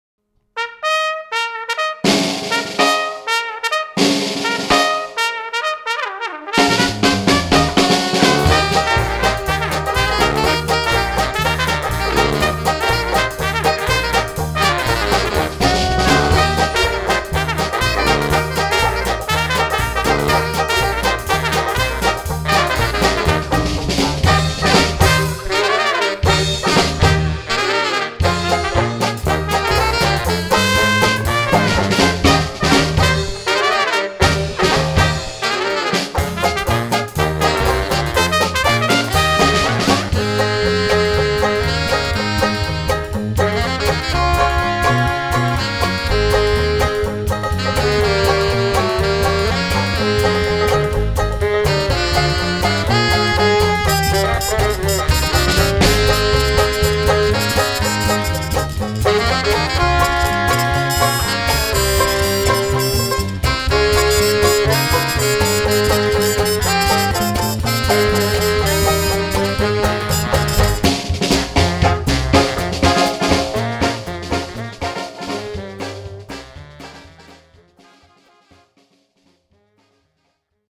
UNPLUGGED - UNVERSTÄRKT - GENIAL